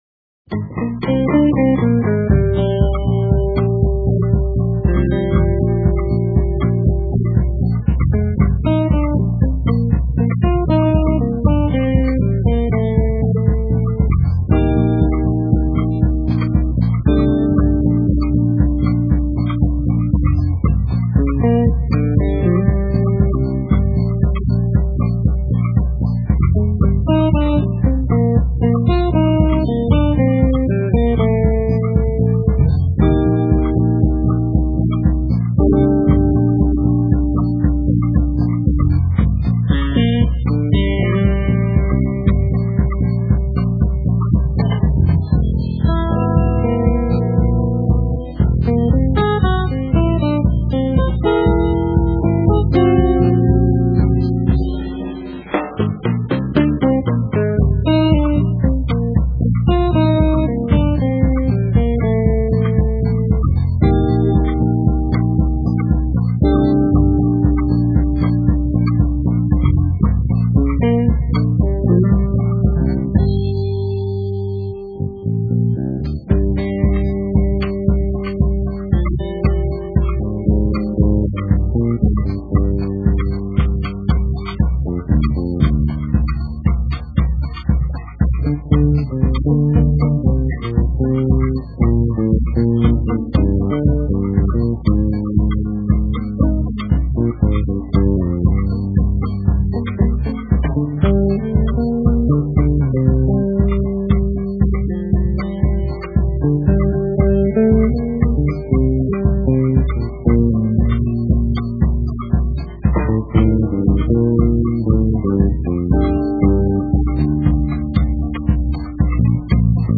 Energetic-Trance-Jazz
guitar, loops
e-bass
drums